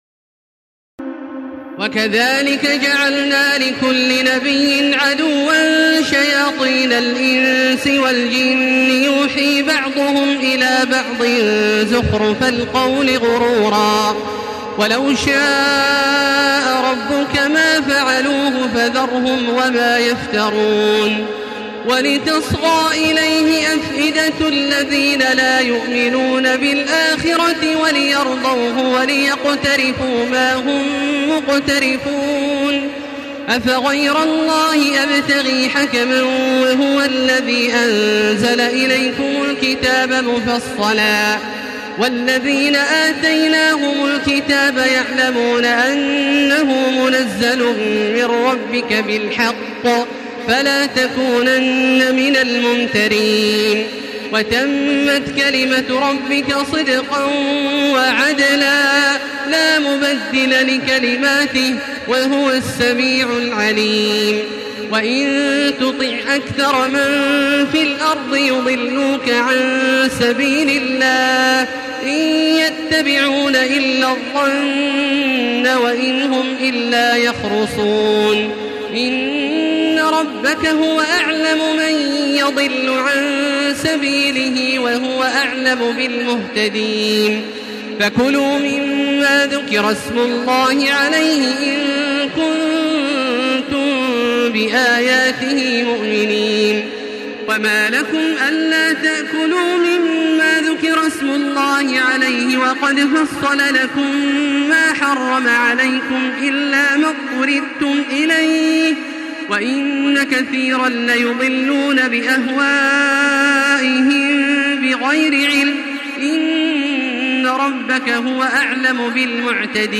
تراويح الليلة الثامنة رمضان 1435هـ من سورة الأنعام (112-165) Taraweeh 8 st night Ramadan 1435H from Surah Al-An’aam > تراويح الحرم المكي عام 1435 🕋 > التراويح - تلاوات الحرمين